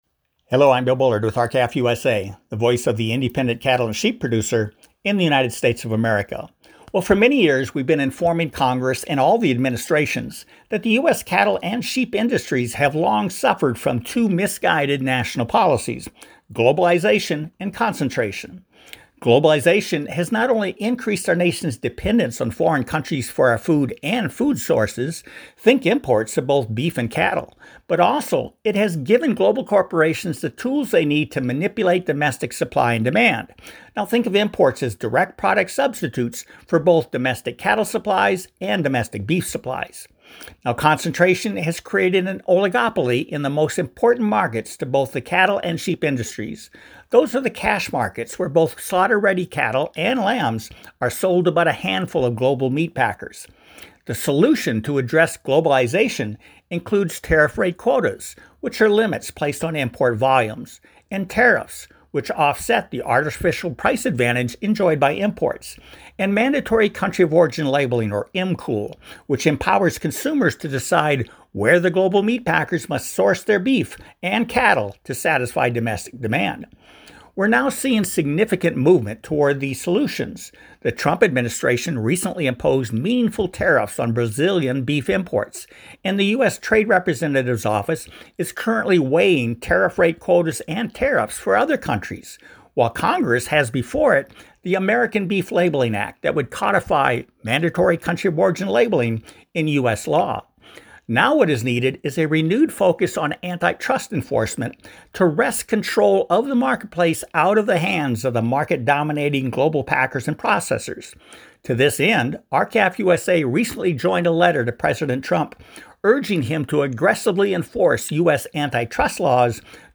This segment was first issued for radio on Oct. 7, 2025. Please find below R-CALF USA’s weekly opinion/commentary that discusses the letter signed by R-CALF USA and dozens of business groups asking President Trump to rigorously enforce U.S. antitrust laws.